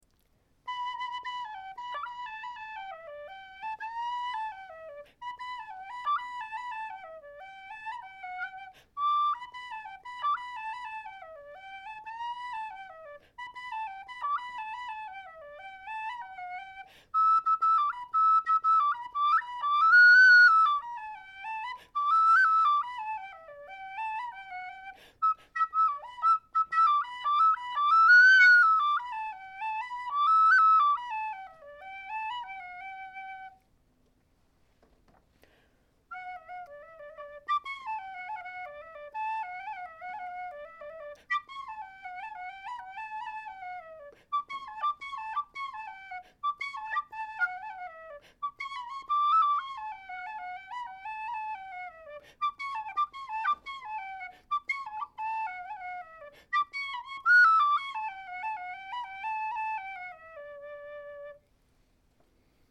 Number: #33 Key: High D Date completed: March 2025 Type: A telescoping brass high D model with brass head and white plastic fipple plug.
Volume: Moderate to loud. A good session whistle, especially for the second octave. High B is strong and clear!